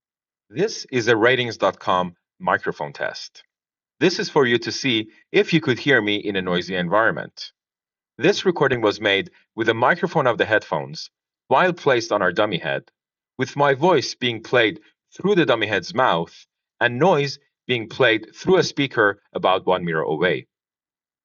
our recording to hear what speech sounds like through the Sony earbuds.